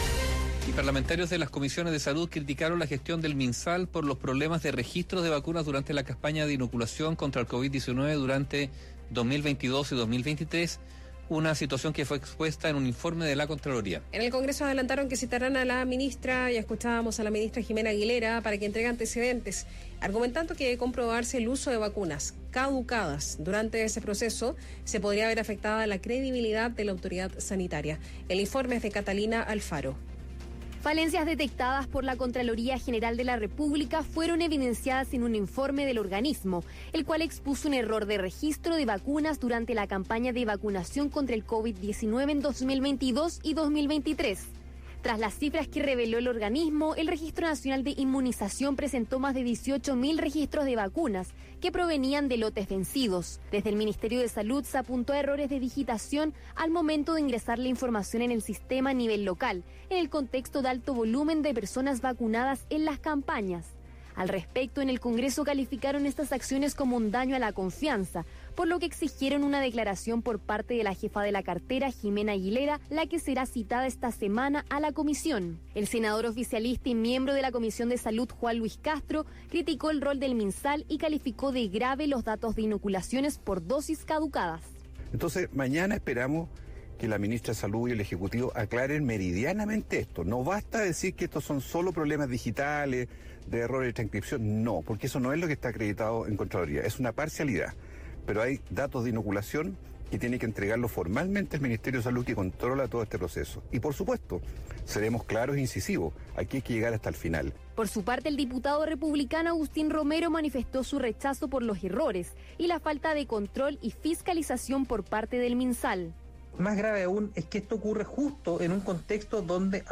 La directora ejecutiva de CIPS-UDD, Paula Daza, conversó con Radio Biobío sobre esta situación.